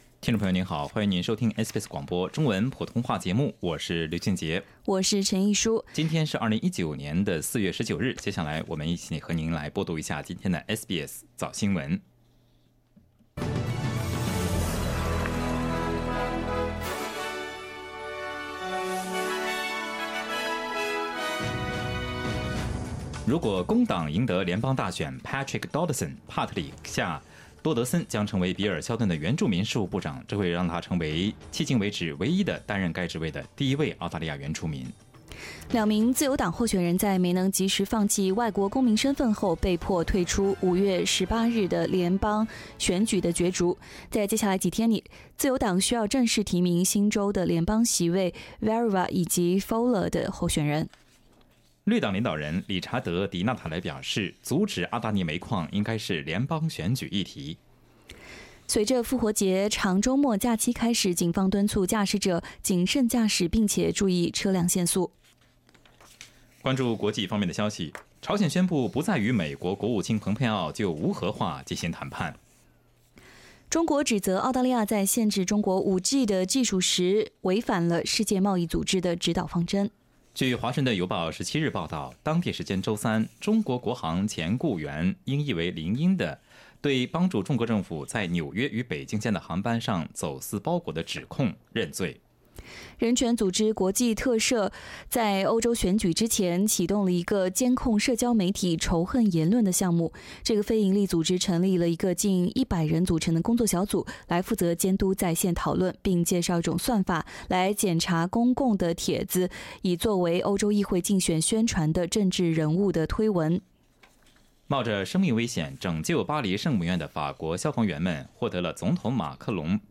SBS 早新闻 （04月19日）